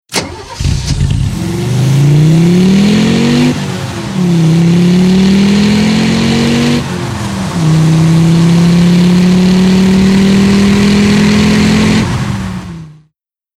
• WRC上为奥迪赢得23个世界冠军的骄人战绩，助奥迪强势复兴，略显低沉的声音彰显强大的实力。